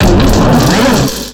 Cri d'Ohmassacre dans Pokémon X et Y.